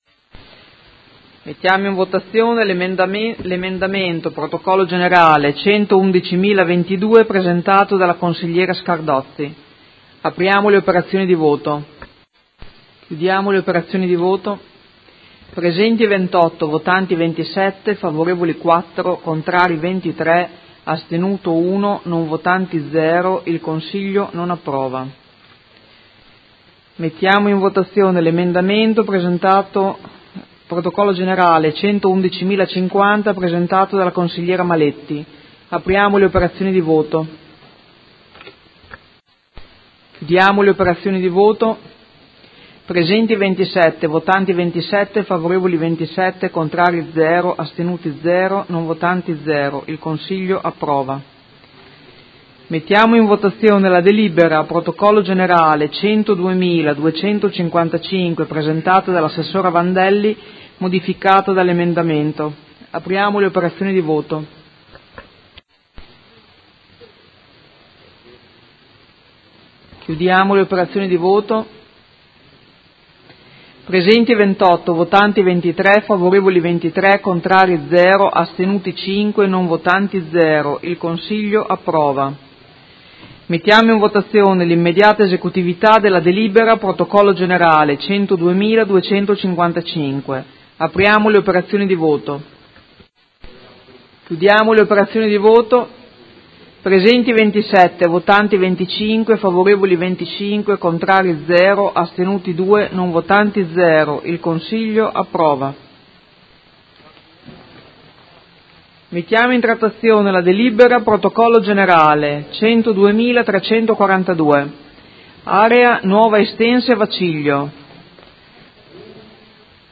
Presidentessa — Sito Audio Consiglio Comunale
Seduta del 19/07/2018 Mette ai voti emendamento nr. 111022.